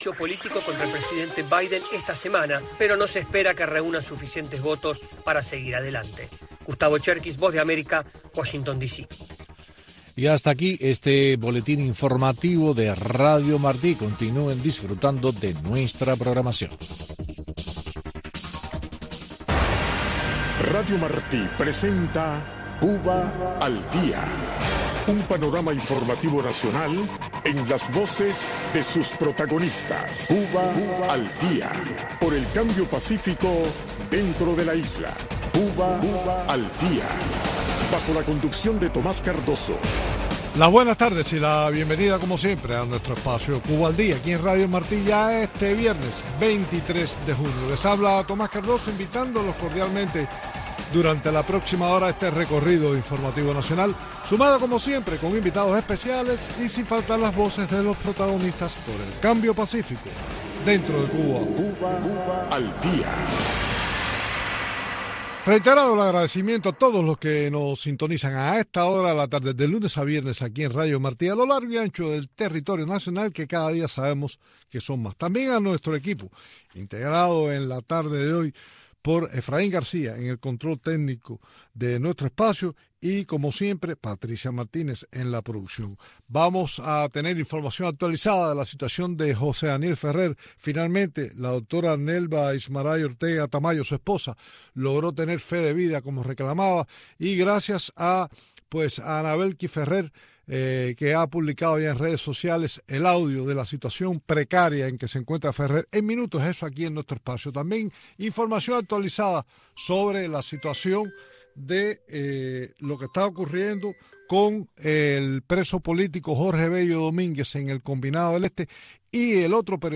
con sus invitados en la isla en este espacio informativo en vivo